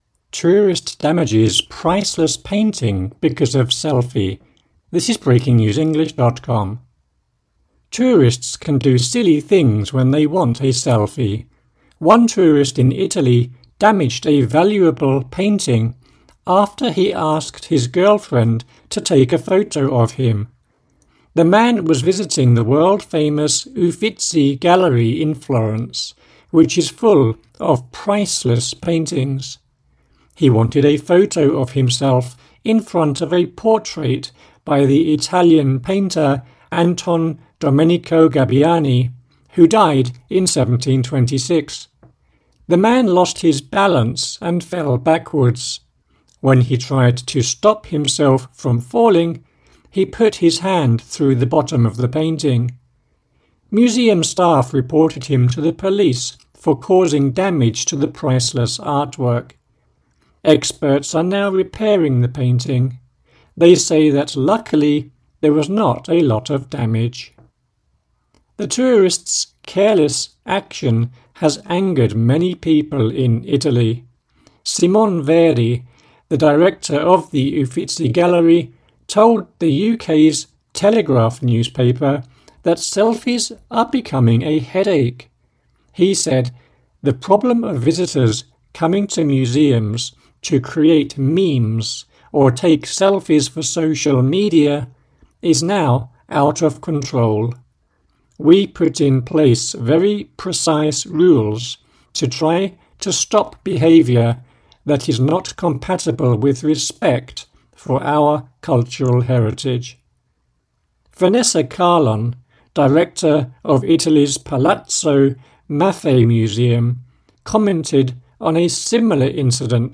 AUDIO (Slow)